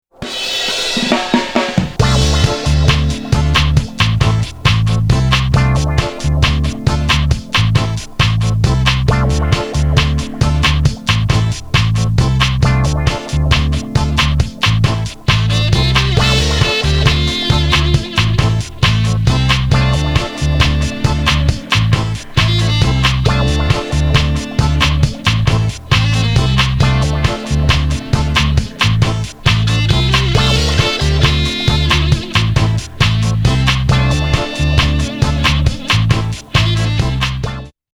フロア映えも抜群！！